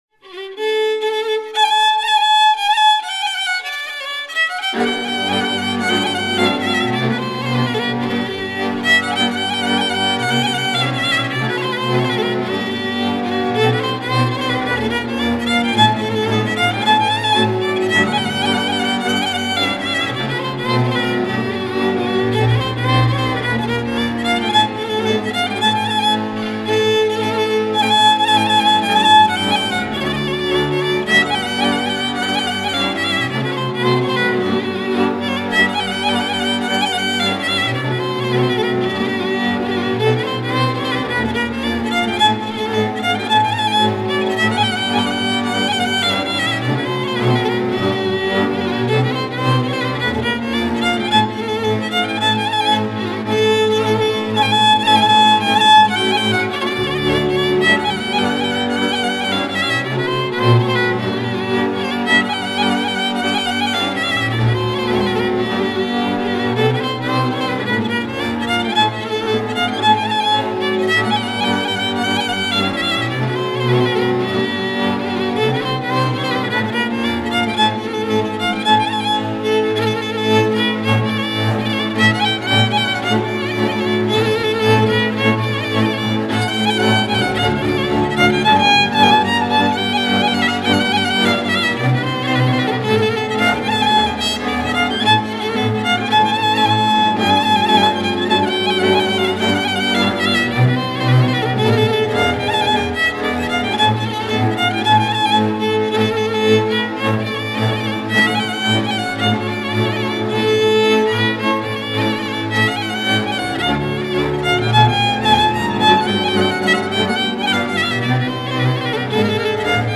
Grupul instrumental din Viișoara